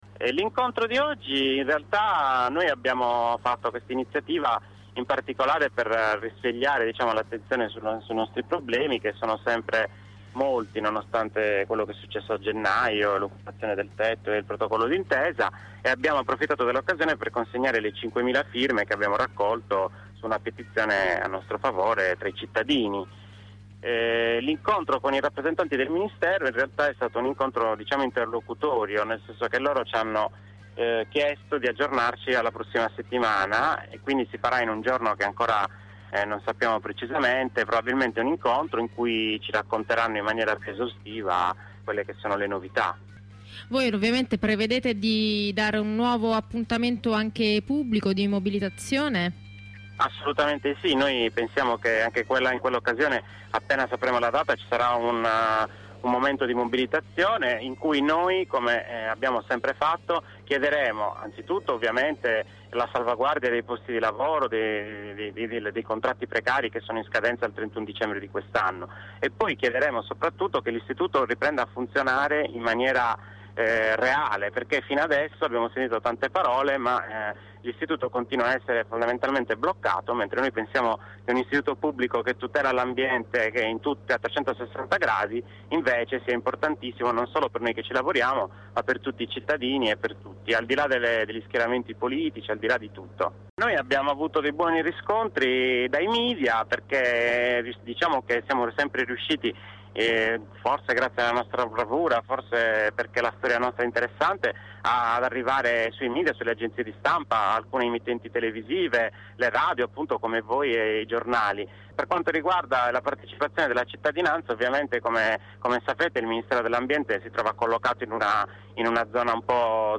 Nelle corrispondenze con ROR una lavoratrice e un lavoratore fanno un resoconto della vicenda in generale e della giornata di oggi in particolare.